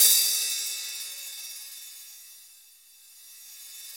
Index of /90_sSampleCDs/Club-50 - Foundations Roland/CYM_xReal HiHats/CYM_x13 Hi Hat 2